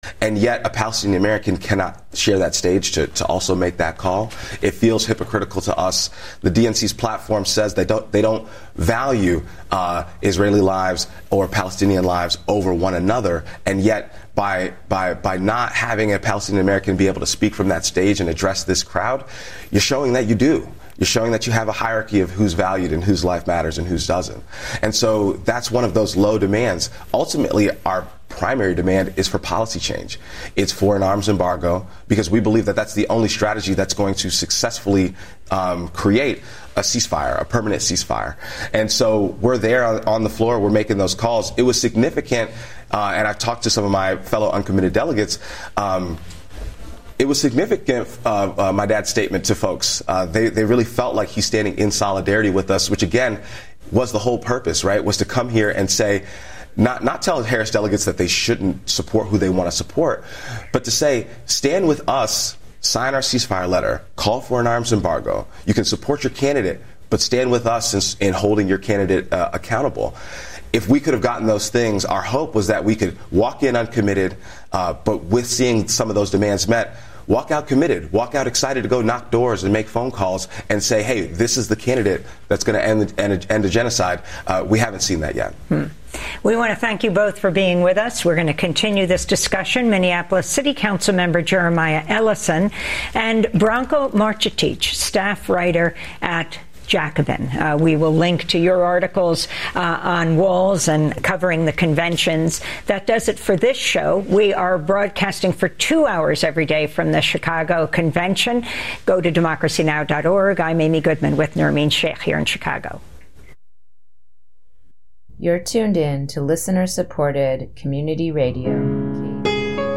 A one-hour morning weekly show, Thursdays at 8:00am